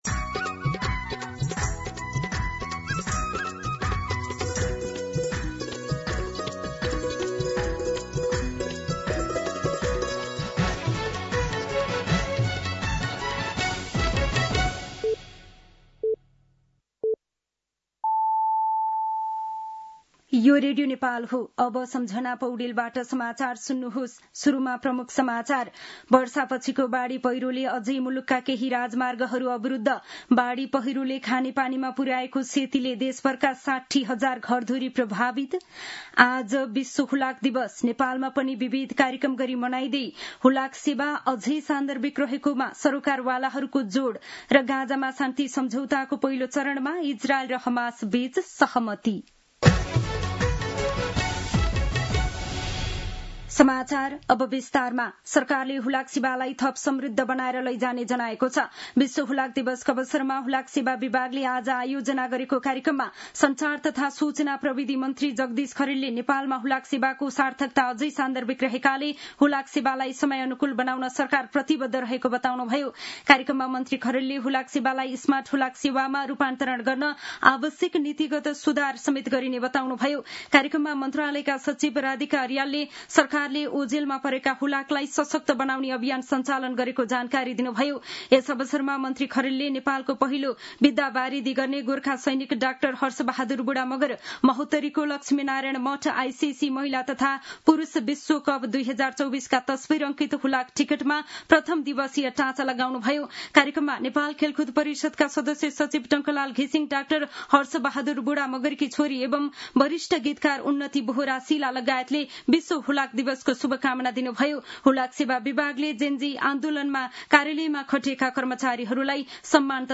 दिउँसो ३ बजेको नेपाली समाचार : २३ असोज , २०८२